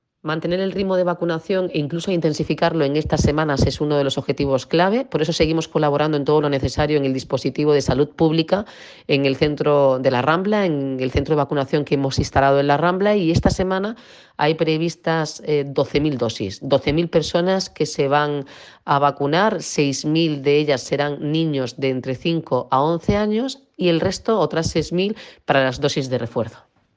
Declaraciones Noelia Arroyo